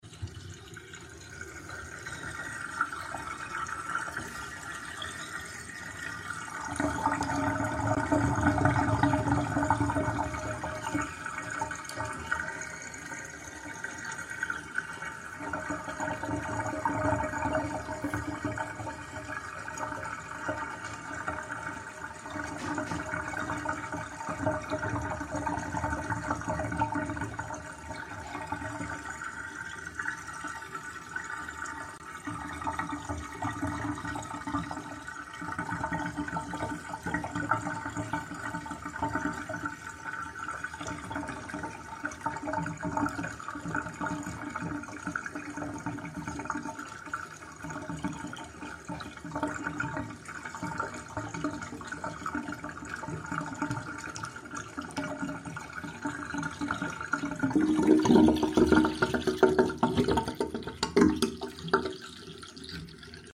Satisfying Whirlpool Sound from my sound effects free download
Satisfying Whirlpool Sound from my bathtub